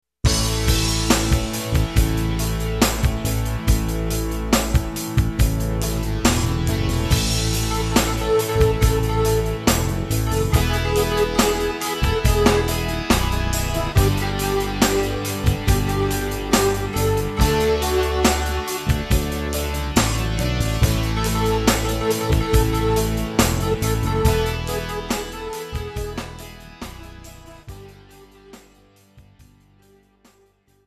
KARAOKE/FORMÁT:
Žánr: Rock